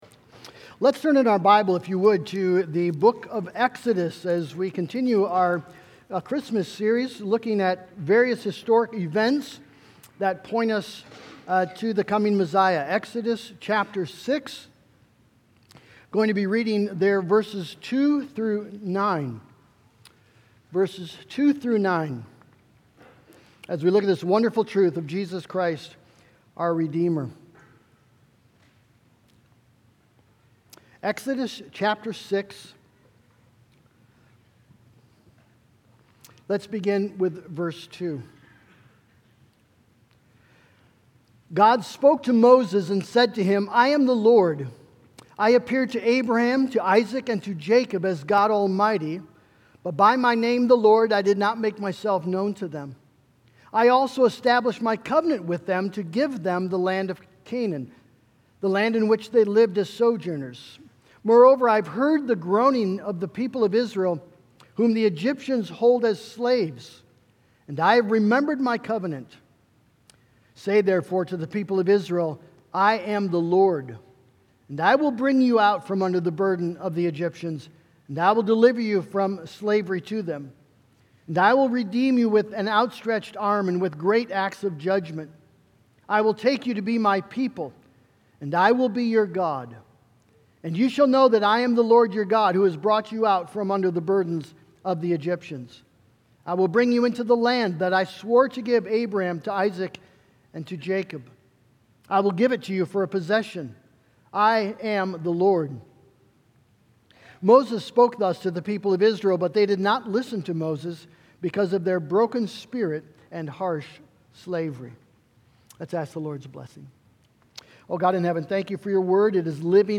Harvest OPC Sermons